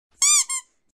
Rubber Duck sound effect
Description: Download Rubber Duck sound effect, hiệu ứng âm thanh tiếng bóp vịt cao su, tiếng bóp vịt đồ chơi âm thanh hoạt hình... edit làm phim, ghép video, chỉnh sửa video.
rubber-duck-sound-effect-www_tiengdong_com.mp3